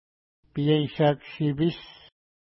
Pronunciation: piejʃa:k-ʃi:pi:s